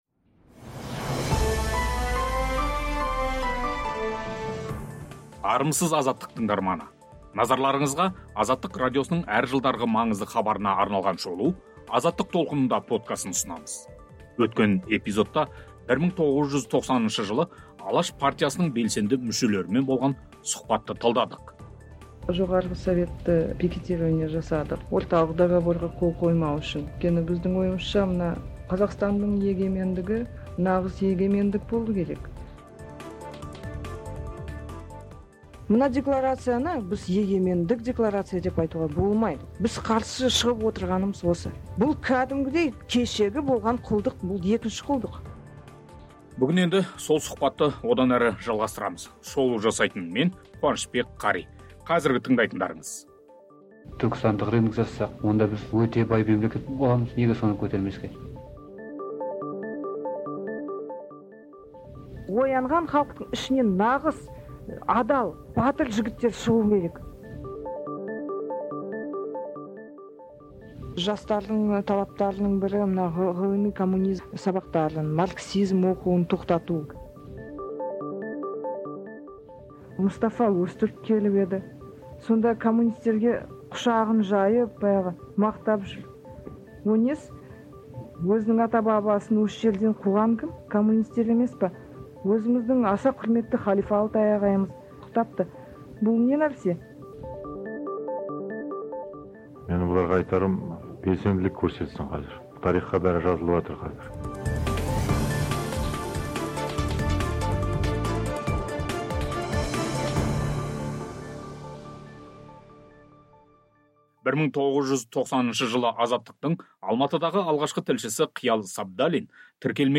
Алматы мен Киевтегі митингілердің соңы не болды? Тіркелмеген "Алаш" партиясының мүшелері Азаттықпен сұхбатында тоқсаныншы жылдарғы өздерінің тұжырым, жоспары, жастар белсенділігі және биліктің қысым тәсілдері жайлы айтады.